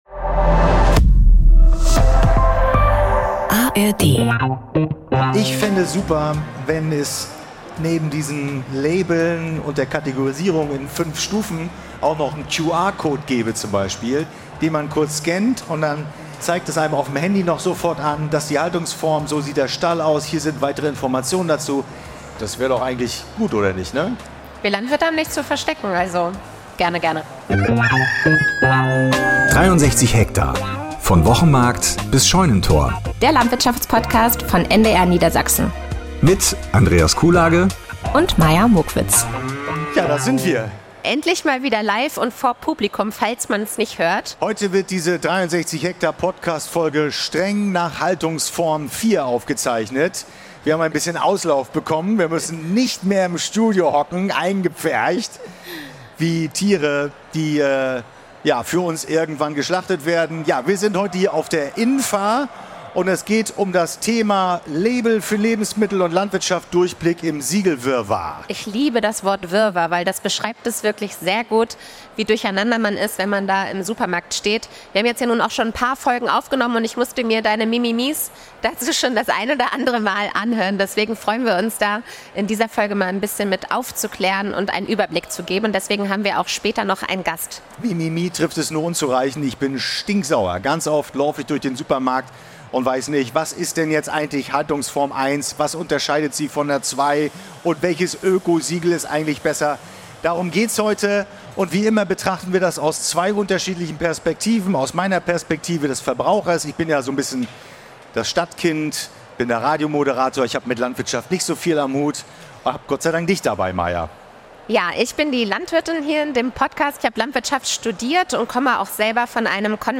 live von der "infa" - der Verbrauchermesse in Hannover.